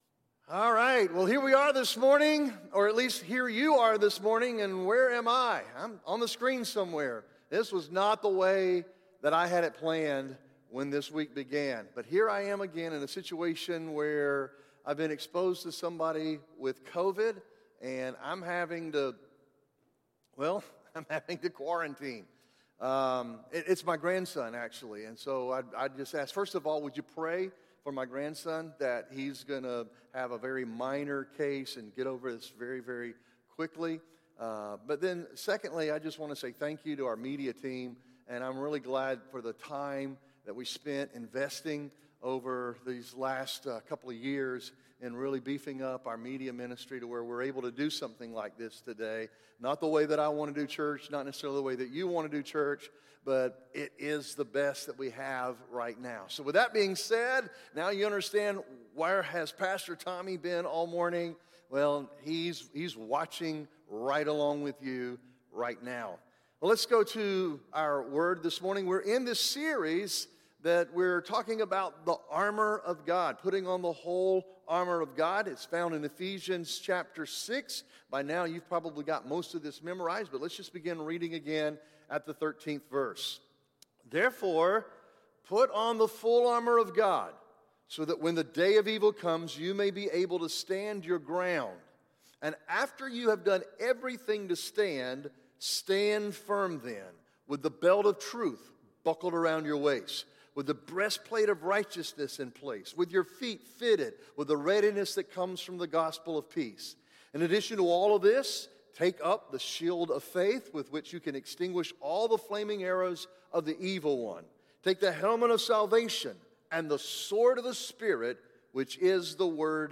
Sermons | SpiritLife Church